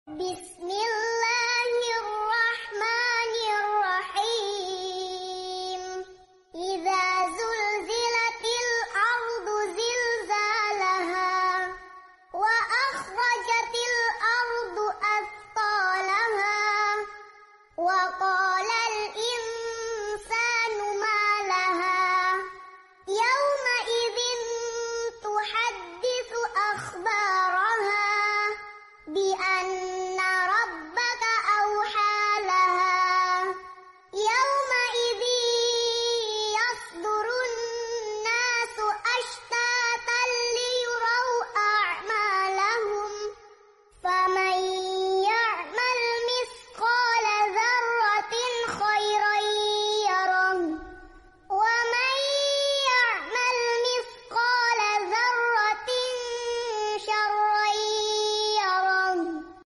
Murottal Anak Juz 30 Metode Ummi